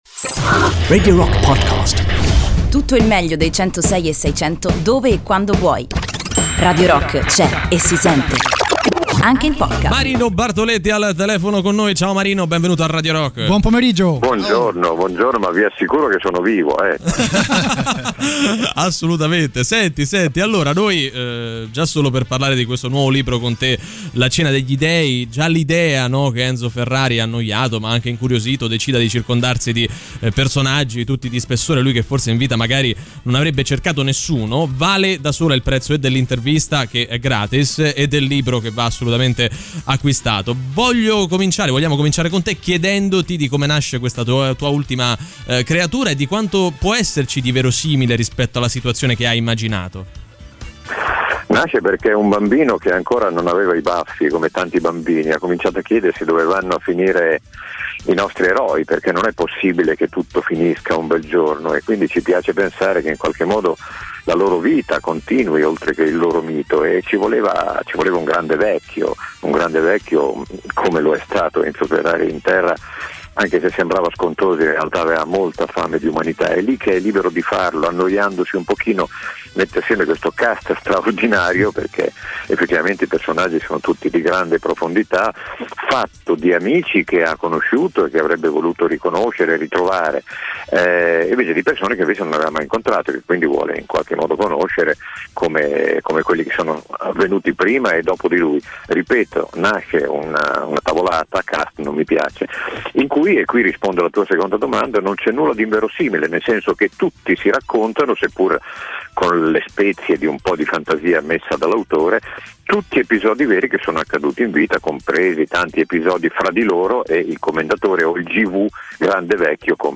Interviste: Marino Bartoletti - La Cena degli Dei (23-12-20)